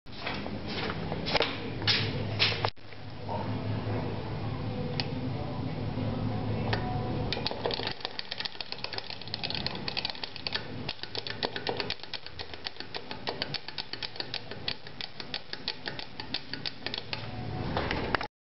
Pressing an Elevator Button Multiple Times